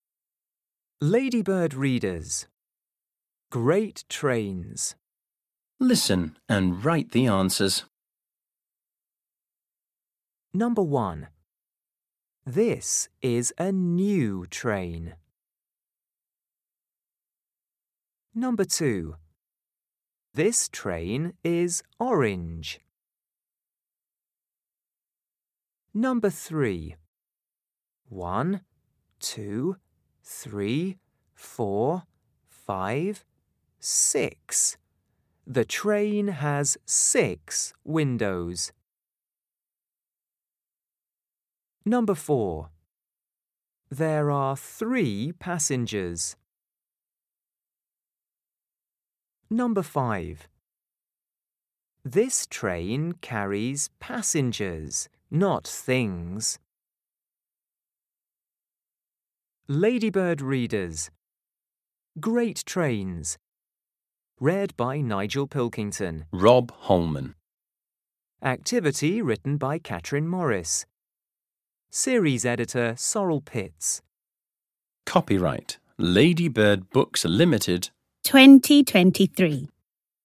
Audio UK